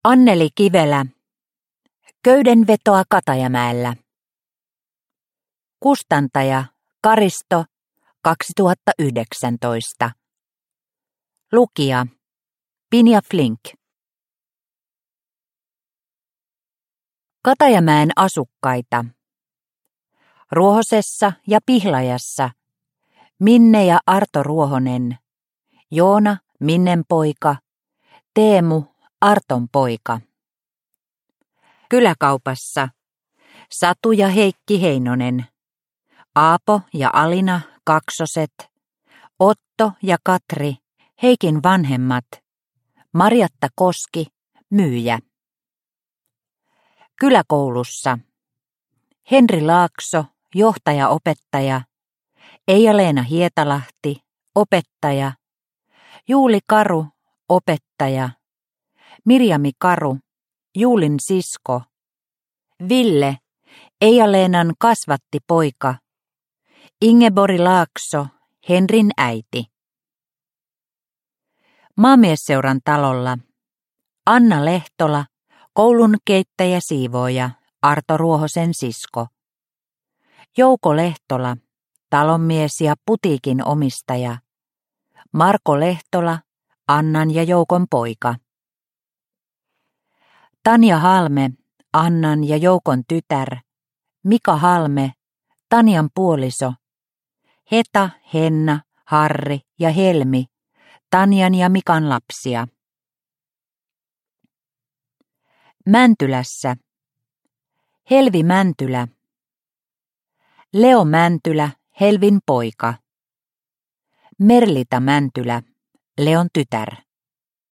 Köydenvetoa Katajamäellä – Ljudbok – Laddas ner